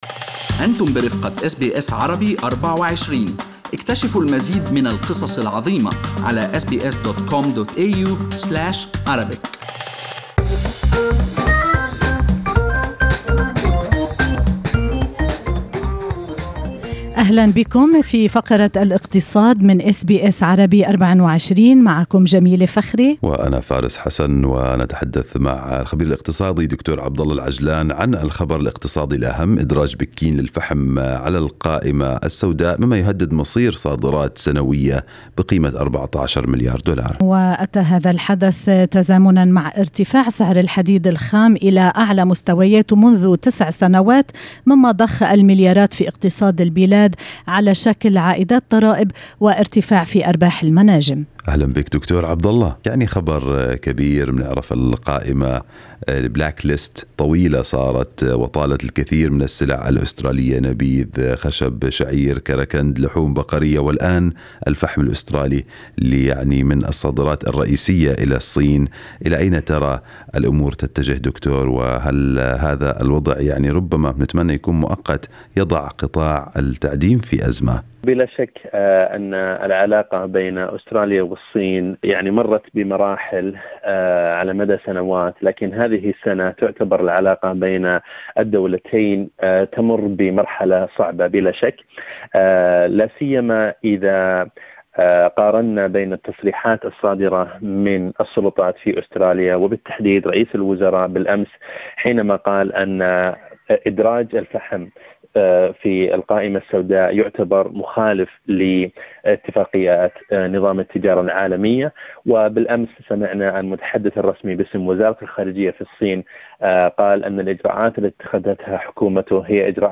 خبير اقتصادي: أستراليا قد تلجأ لزيادة صادرات الفحم إلى اليابان والهند لتعويض خسائر الصين